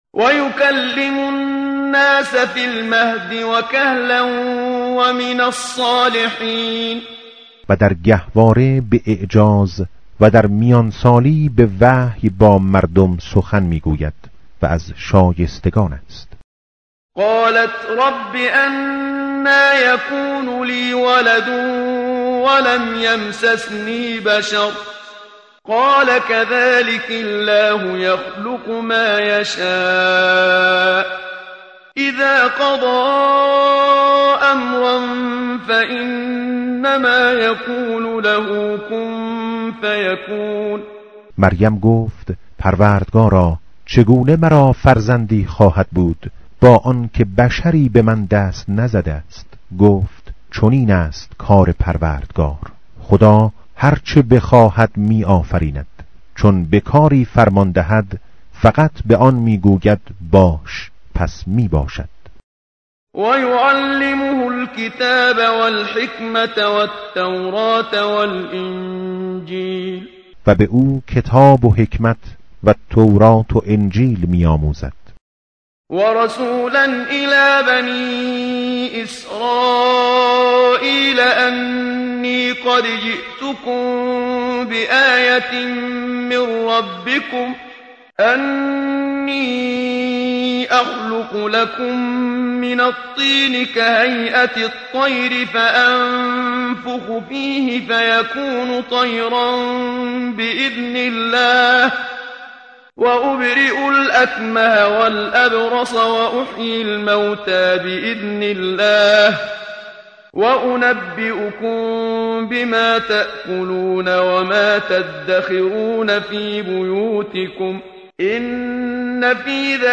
متن قرآن همراه باتلاوت قرآن و ترجمه
tartil_menshavi va tarjome_Page_056.mp3